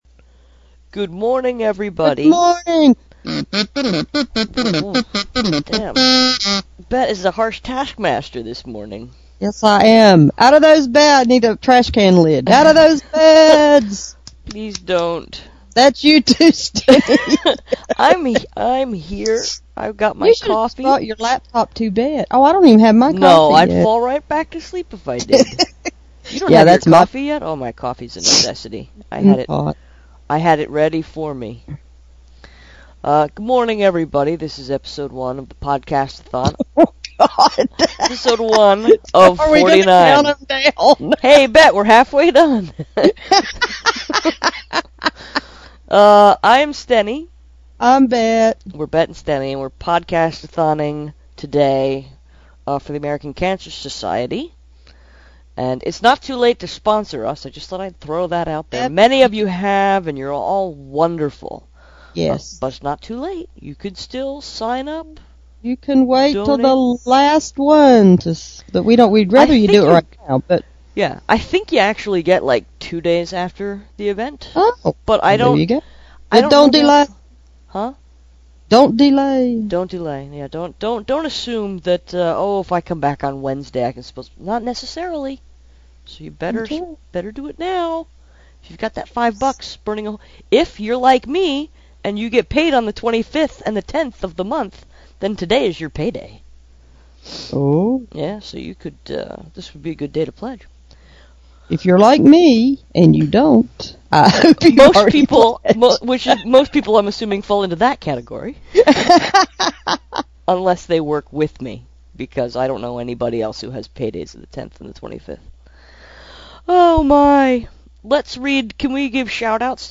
:  In which we welcome you to Blogathon 2009 and sleepily give you rules to all our sponsors, promoters and supporters.